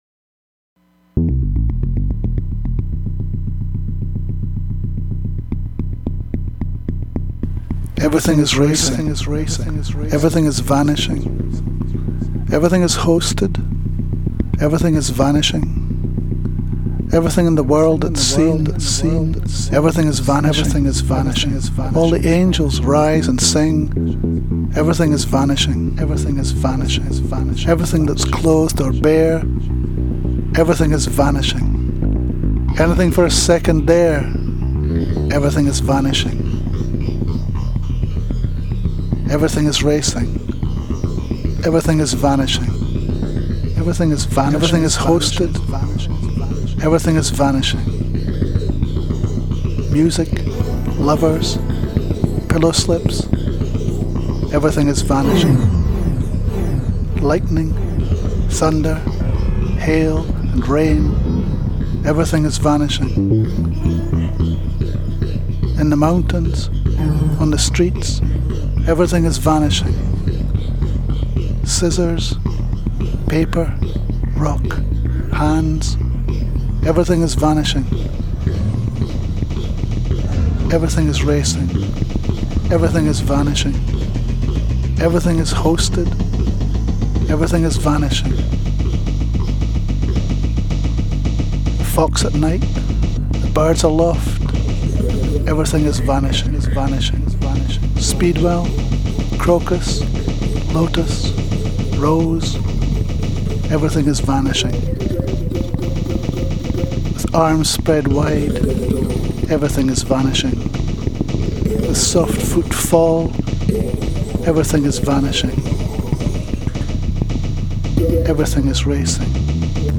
§ There's a lot of echo on some of these -- blame it on Elvis and the Memphis slap back sound.
We were after the experimental, the migration into electronic sound-text narrative, where noise is just as important as language.
I was using an Akai GX 280D which had stereo echo, unlike the ReVox A77 which had mono echo, so the Akai was an advance in terms of spacial imaging.